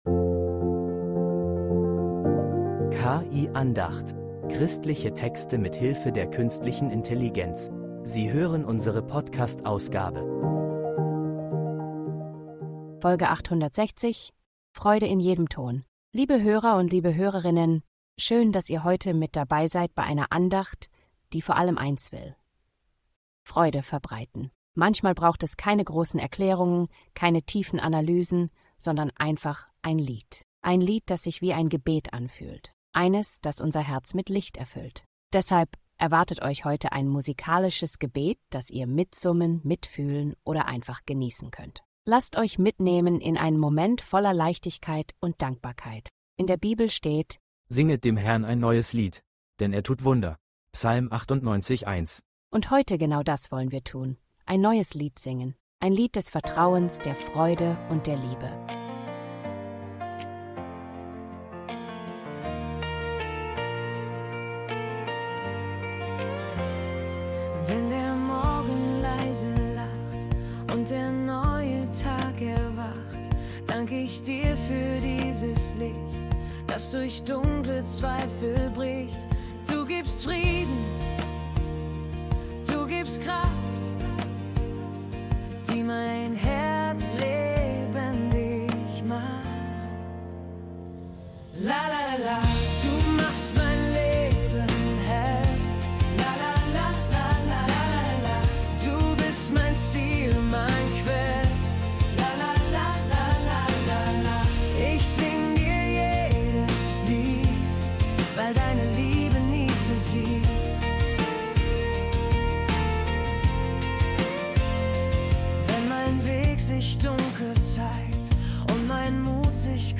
Heute erwartet dich keine klassische Andacht – sondern ein Lied.